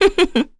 Chrisha-Vox-Laugh_kr.wav